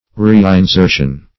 Reinsertion \Re`in*ser"tion\ (-s?r"sh?n), n.